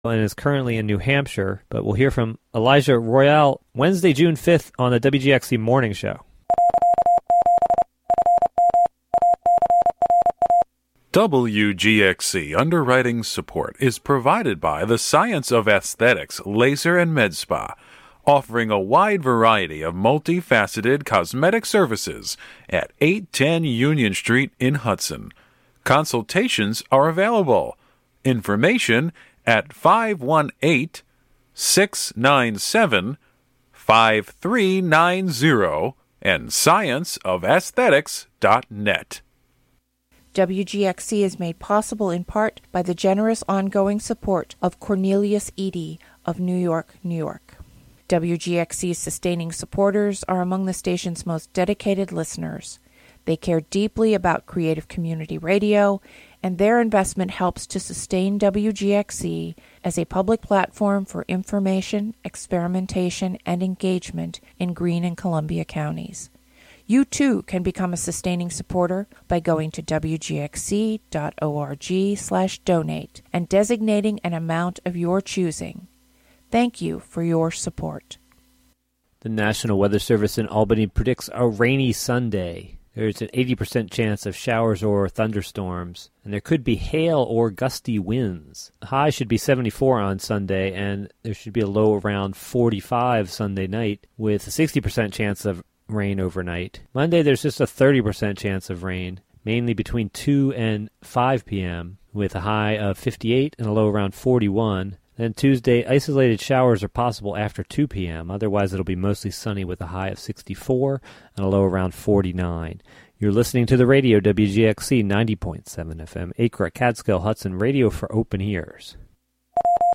A radio project by the Youth Clubhouse of Columbia-Greene Counties in Catskill live on Fridays as part of All Together Now! and rebroadcast Sunday mornings.